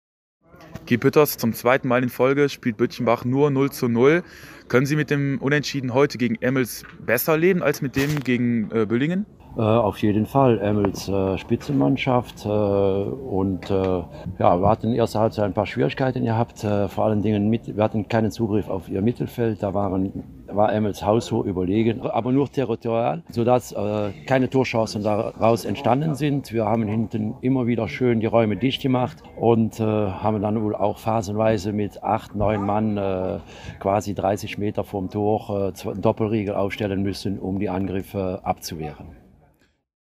vor Ort